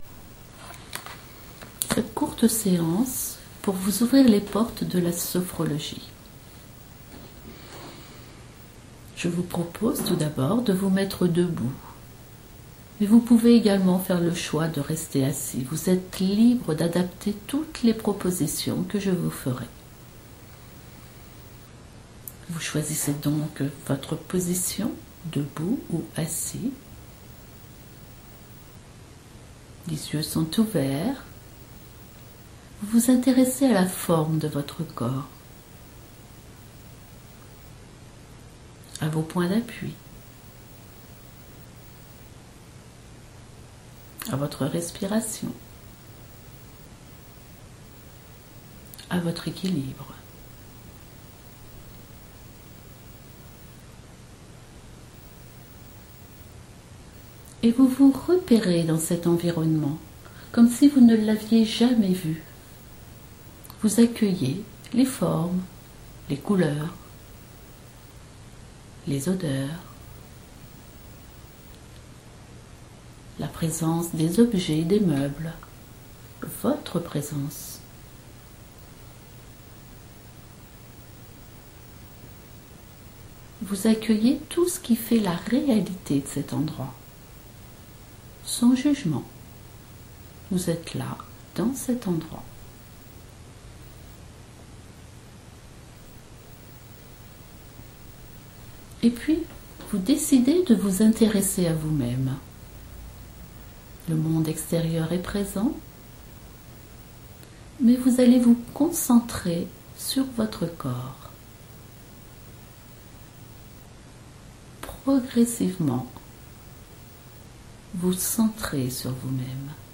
Pratique sophro audio
sc3a9ance-sophro-audio-gratuite.mp3